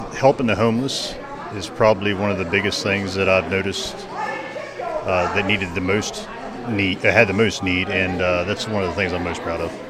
The Potomac Highlands United Way hosted a volunteer recognition breakfast at Brookdale farms Tuesday morning.